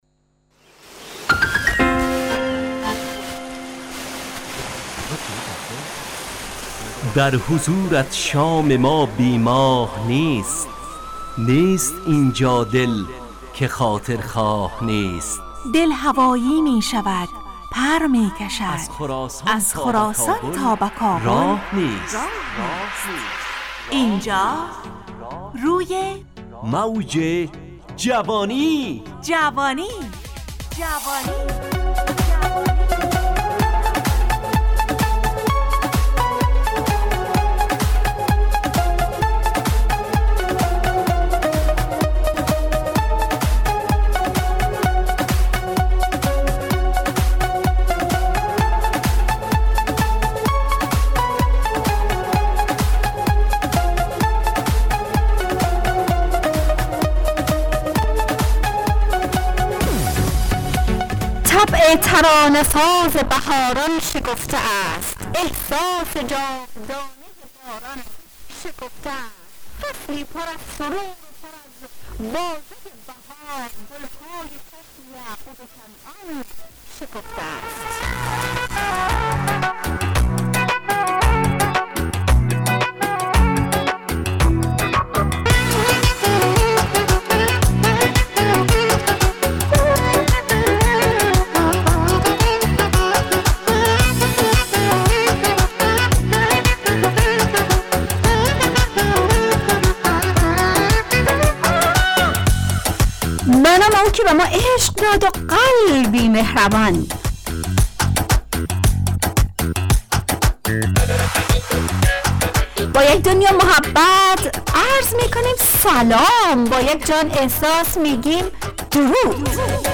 همراه با ترانه و موسیقی مدت برنامه 70 دقیقه .